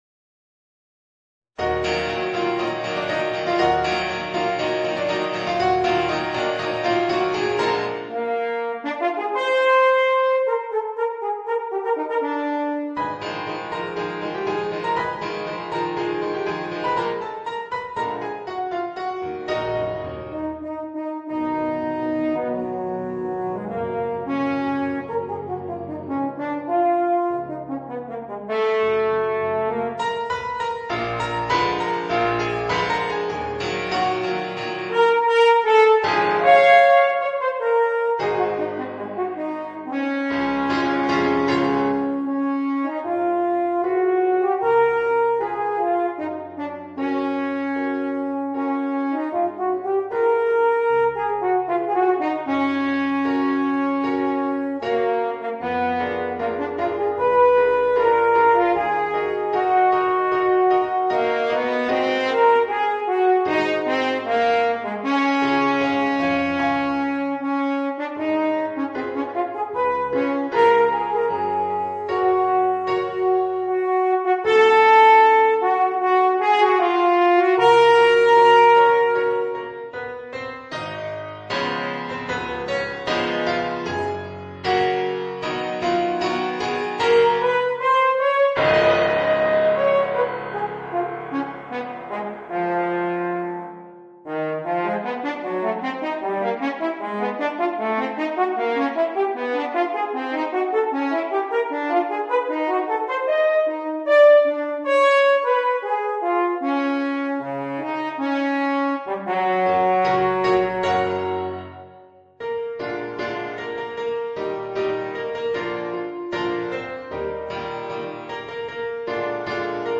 Voicing: Eb Horn and Piano